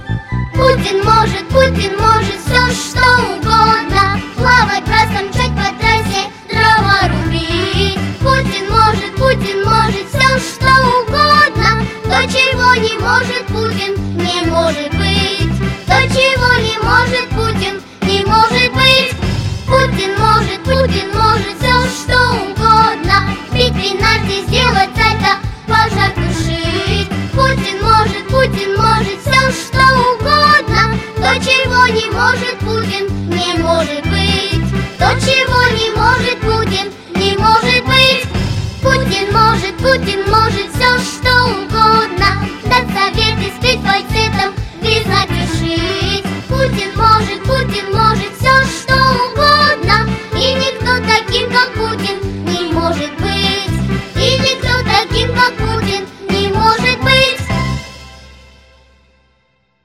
• Качество: 148, Stereo
веселые
детский хор